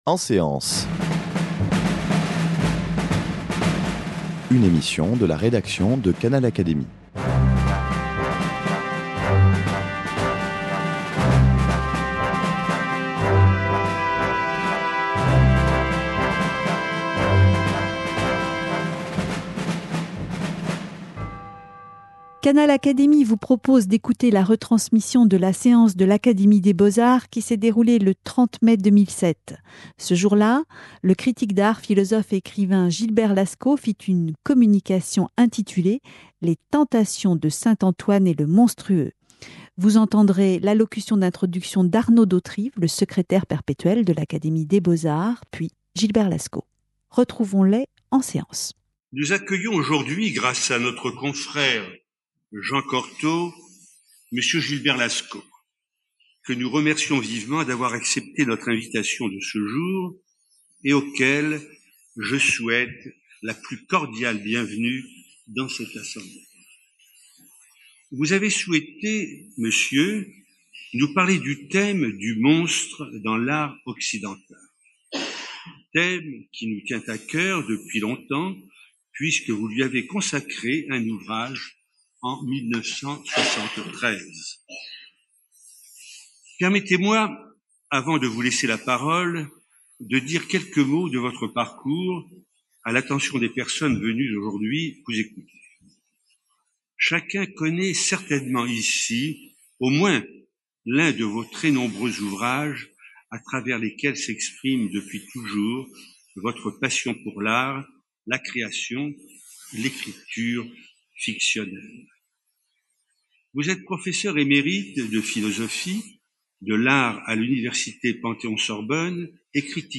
devant les membres de l’Académie des beaux-arts